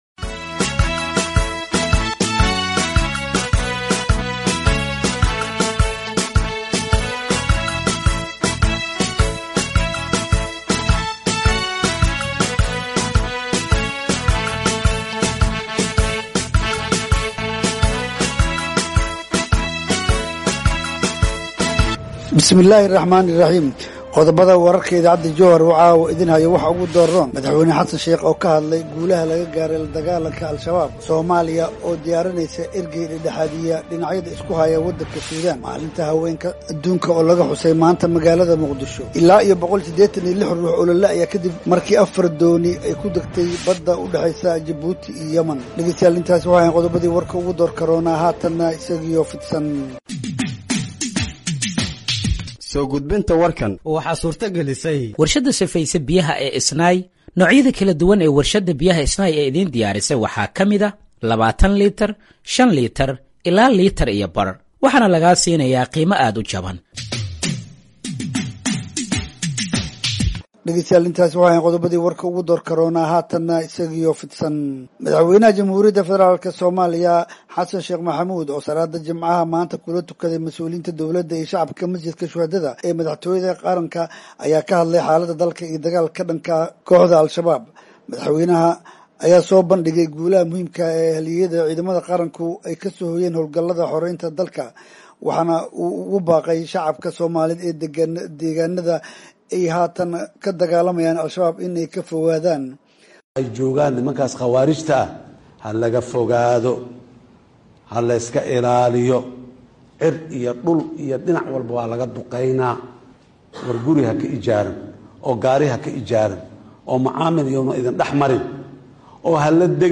Dhageeyso Warka Habeenimo ee Radiojowhar 07/03/2025